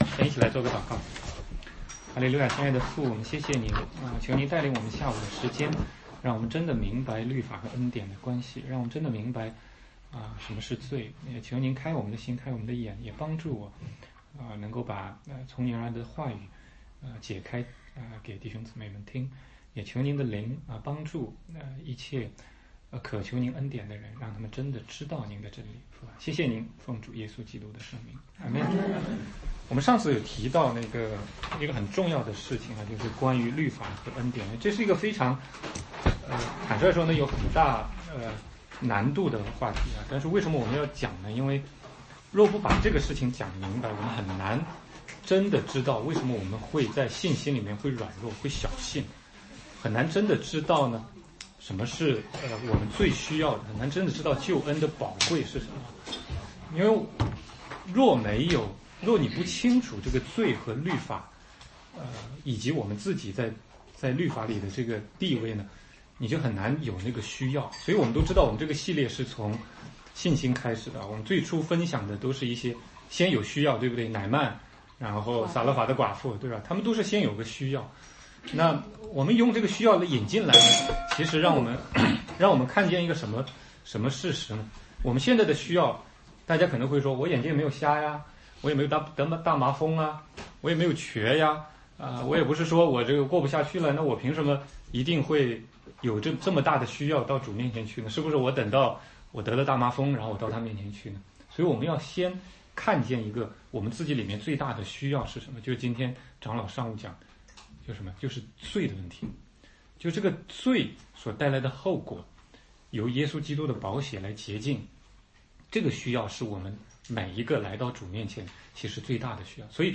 16街讲道录音 - 信心6